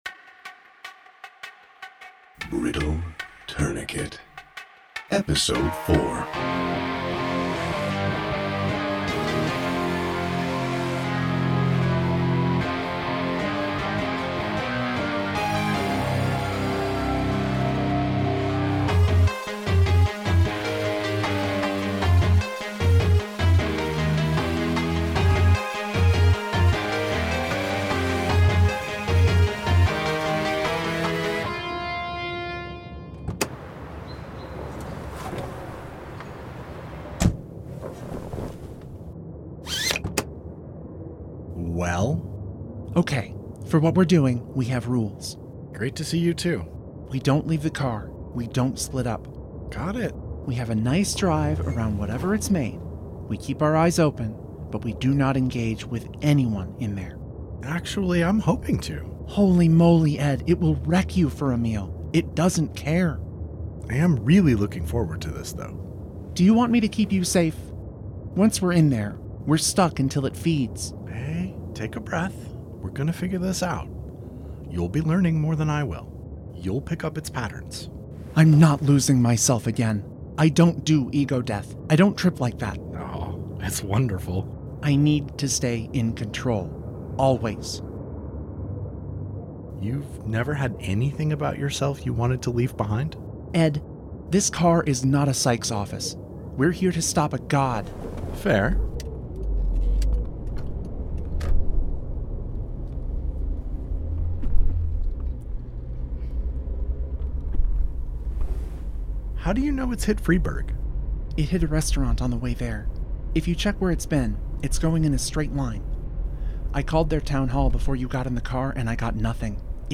Brittle Tourniquet – Episode 4 – Brittle Tourniquet – Audio Drama